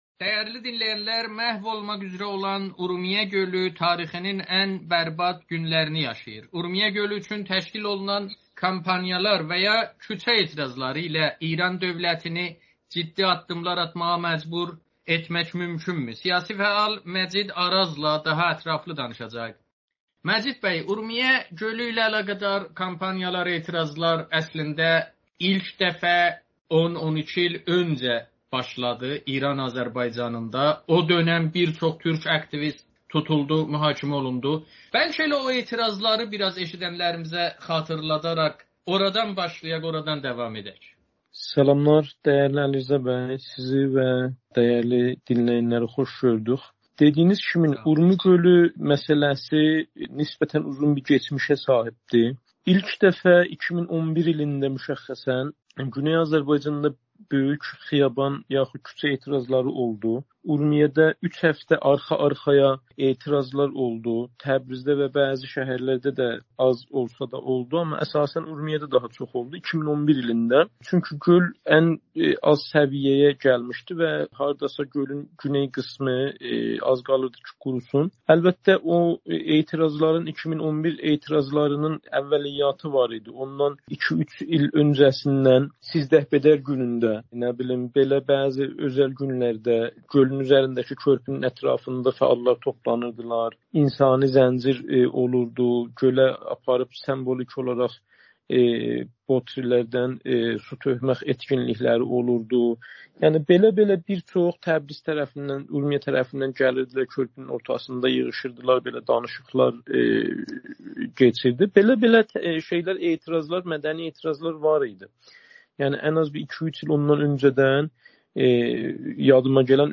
Amerikanın Səsi ilə söhbətdə Urmiyə Gölü ətrafında yaşanan ekoliji faciə və bu vəziyyətə etiraz olaraq İran Azərbaycanında təşkil olunan kampaniyalar və aksiyalar haqqında danışıb.